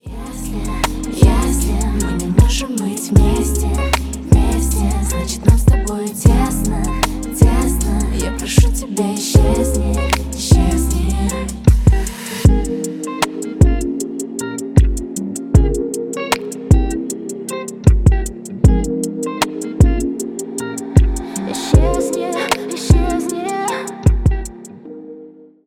Поп Музыка
тихие
спокойные